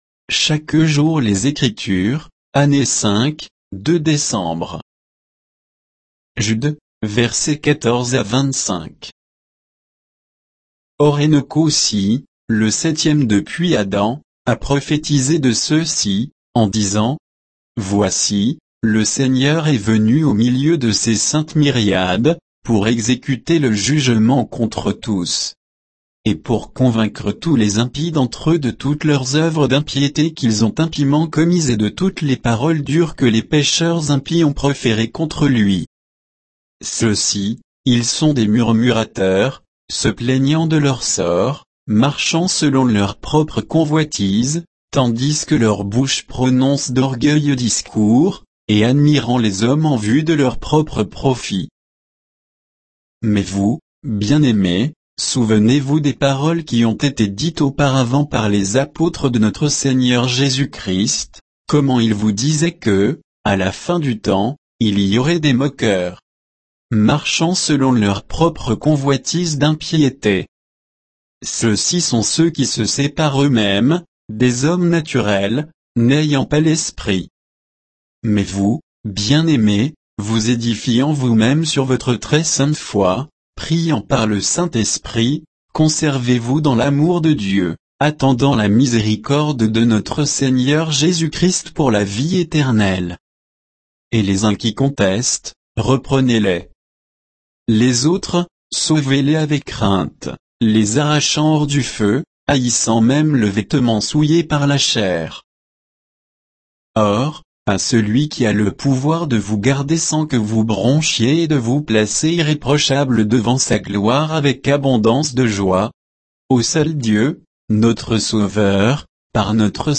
Méditation quoditienne de Chaque jour les Écritures sur Jude 14 à 25